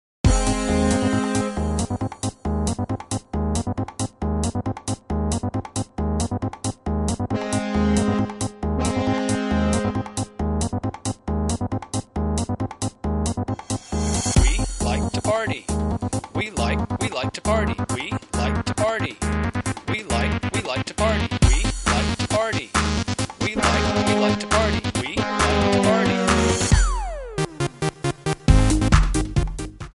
F#
Backing track Karaoke
Pop, 1990s